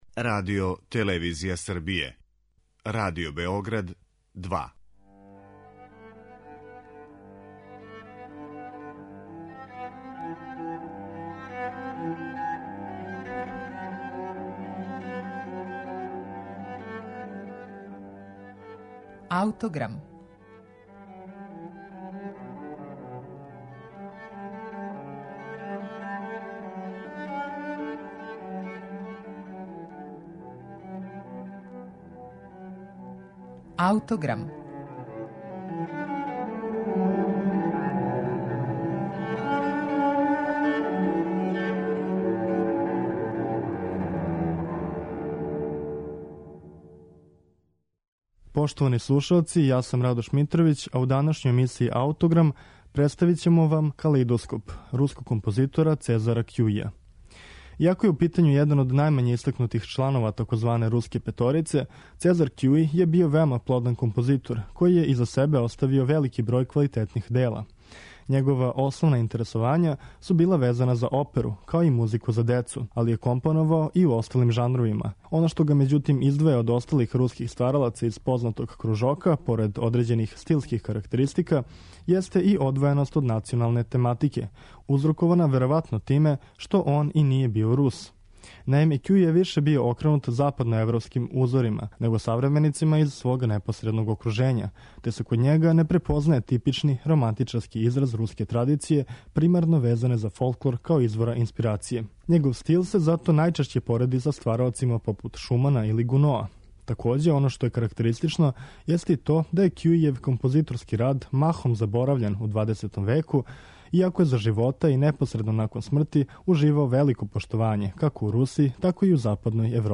Сезар Кјуи: Калеидоскоп, за виолину и клавир
Калеидоскоп-сложено дело сачињено од 24 карактерно контрастна става које равноправно третира виолину и клавир.